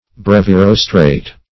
Search Result for " brevirostrate" : The Collaborative International Dictionary of English v.0.48: Brevirostral \Brev`i*ros"tral\, Brevirostrate \Brev`i*ros"trate\, a. [L. brevis short + E. rostral, rostrate.]
brevirostrate.mp3